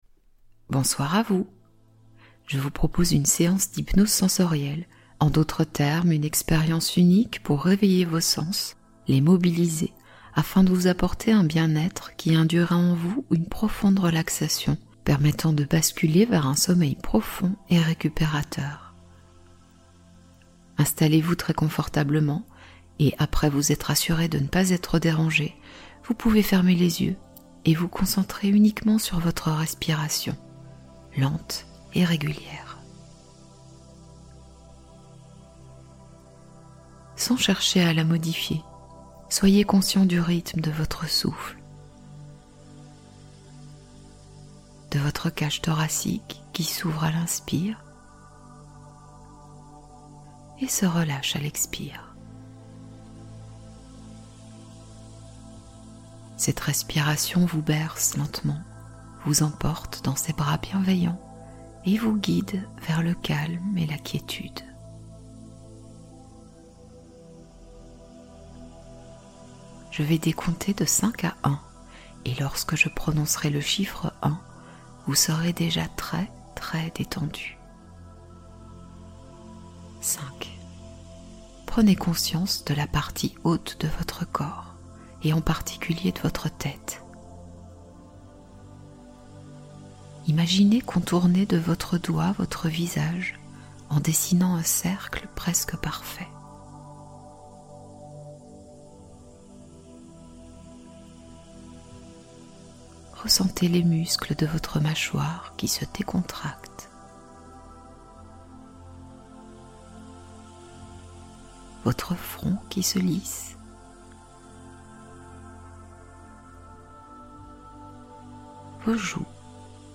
Berceuse de Nature : Immersion sonore pour un sommeil magique